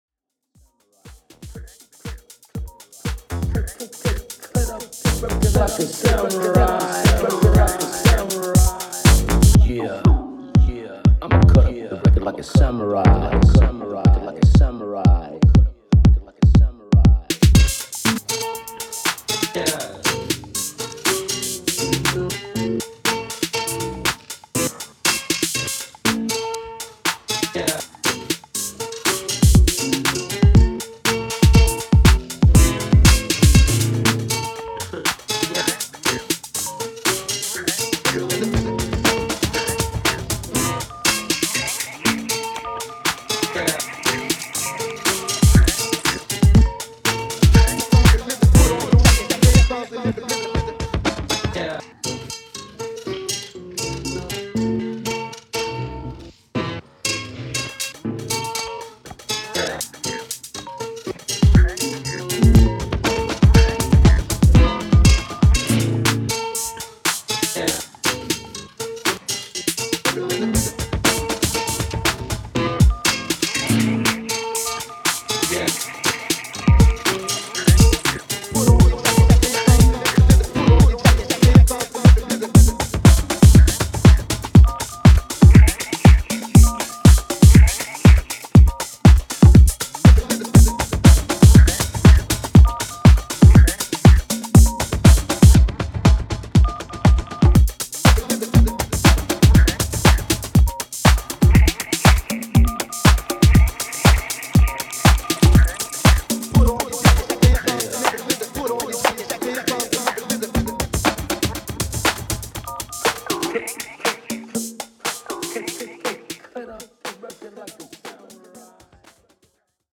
いずれの楽曲からもそれぞれの個性が溢れており、広範なタイプのミニマル・ハウスを収録した一枚です！